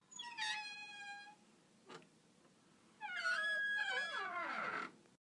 中级音响库 " 关闭；门溪
描述：一扇非常吱吱作响/吱吱作响的门关闭。记录非常接近。
Tag: 关闭 关闭 生锈 吱吱响 开口 小溪